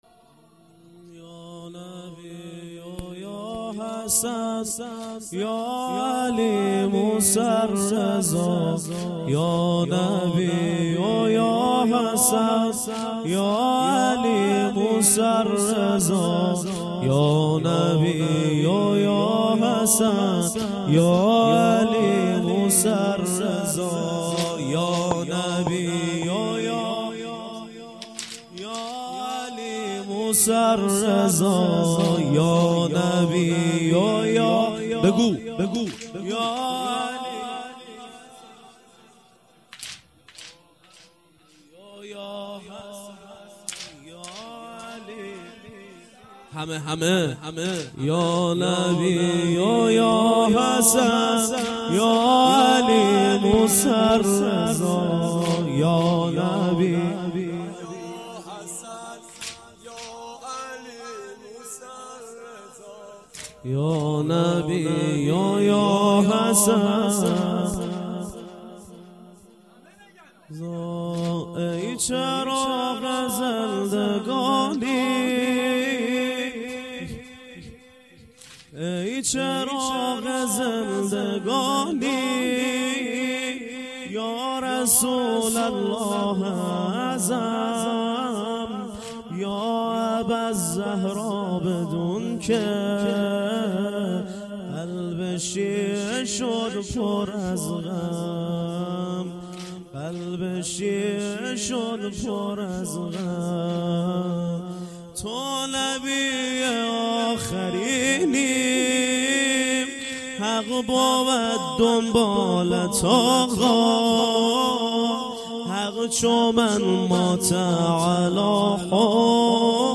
واحد - یا نبی و یا حسن یا علی موسی الرضا